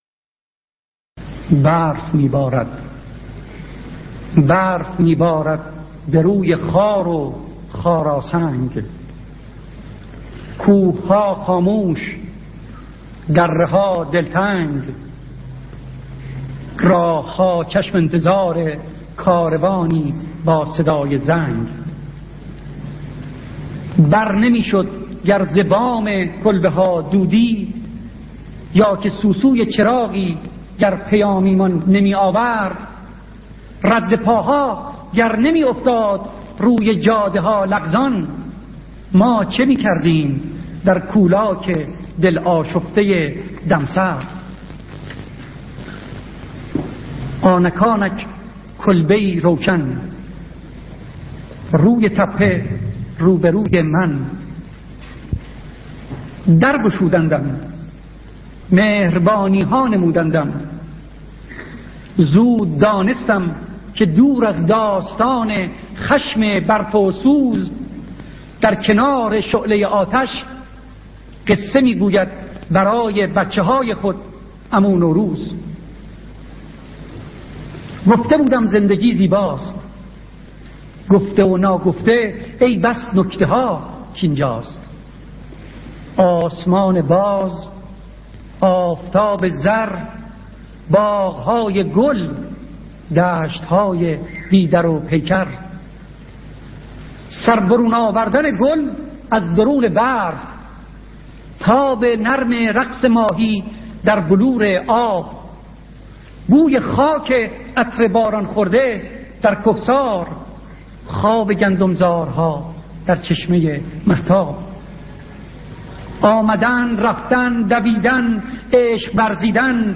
شعر "آرش کمانگیر" از سیاوش کسرایی با صدای شاعر